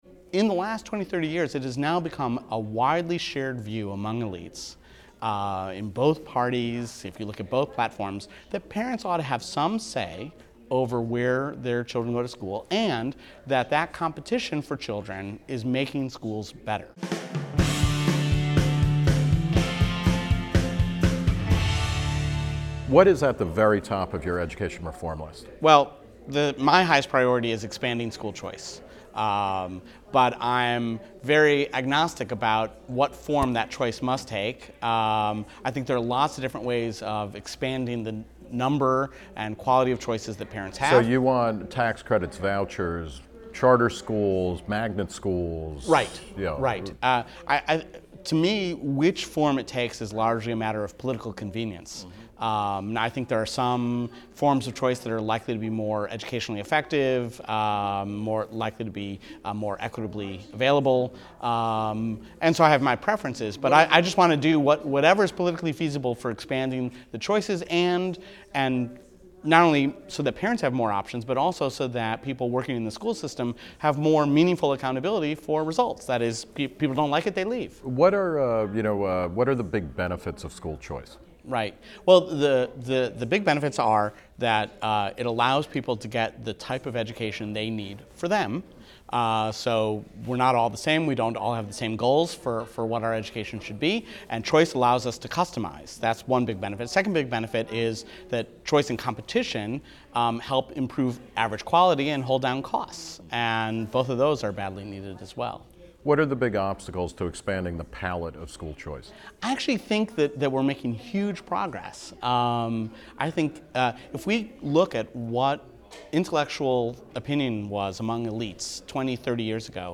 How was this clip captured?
at the National Summit on Education Reform in Washington, D.C.